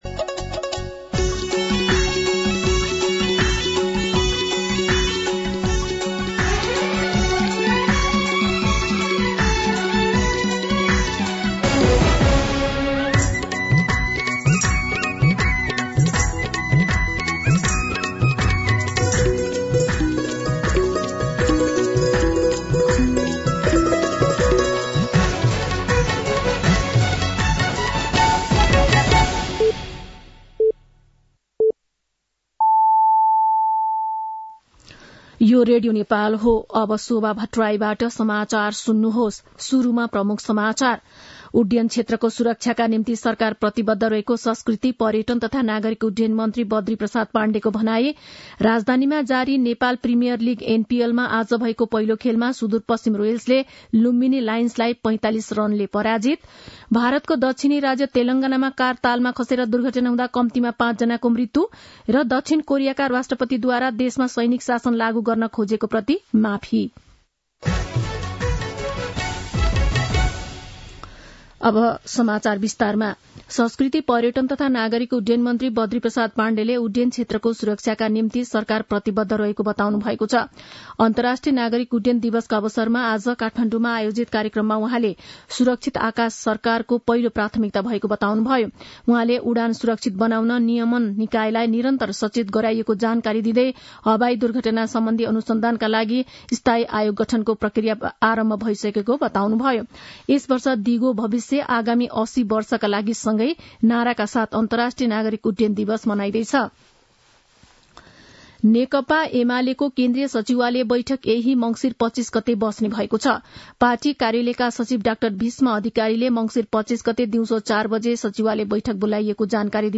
दिउँसो ३ बजेको नेपाली समाचार : २३ मंसिर , २०८१
3-pm-Nepali-News.mp3